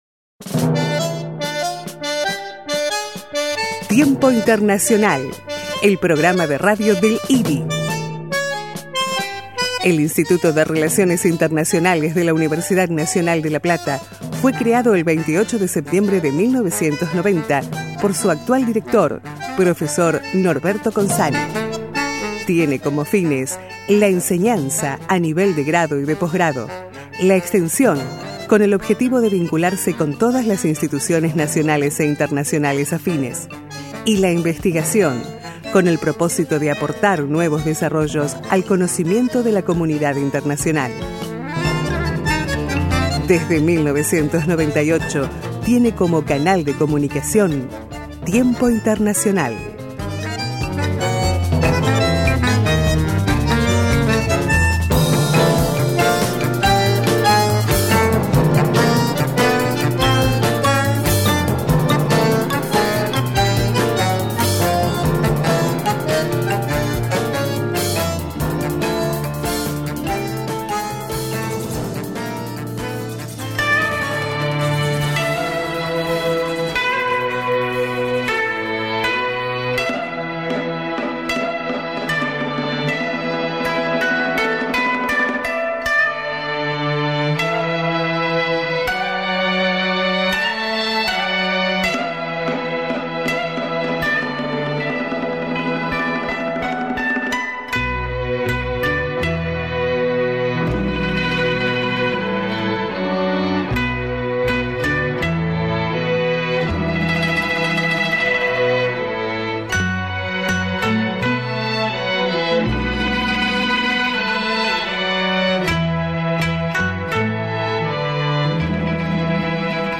Noticias y entrevistas